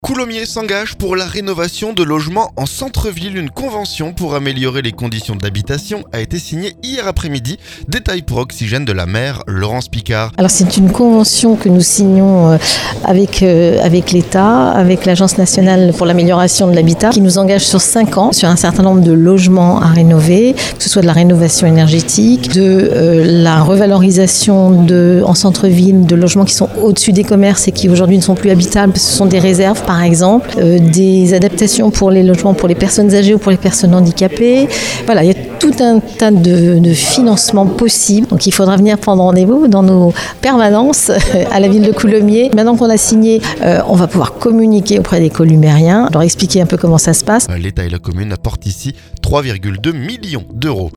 Coulommiers s'engage pour la rénovation de logements en centre-ville. Une convention pour améliorer les conditions d'habitation a été signée mardi après-midi. Détails pour Oxygène de la maire Laurence Picard.